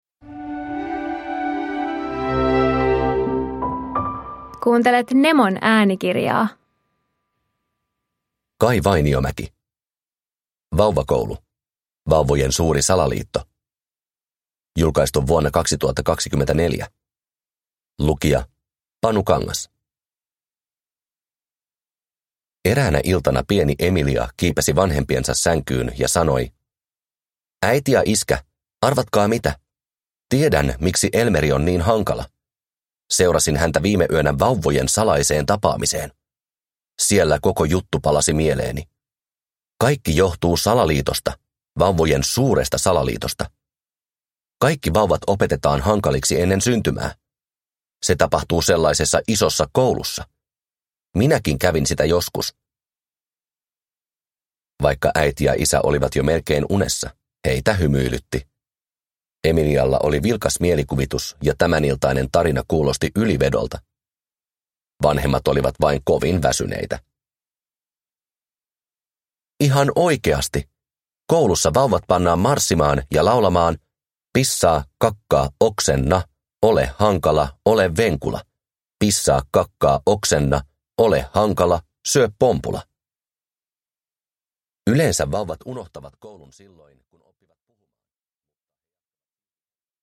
Vauvakoulu – Ljudbok